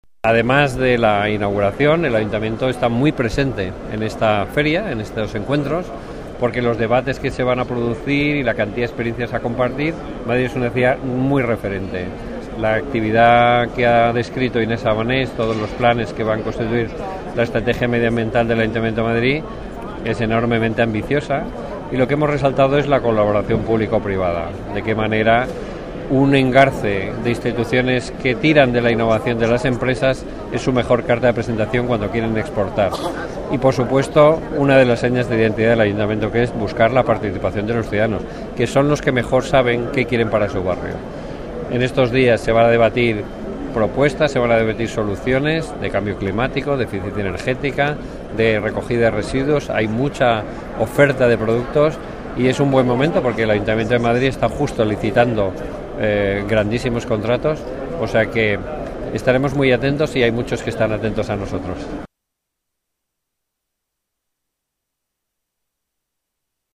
En el Foro de las Ciudades de Madrid hasta el 17 de junio en IFEMA
Nueva ventana:Luis Cueto, coordinador general Alcaldía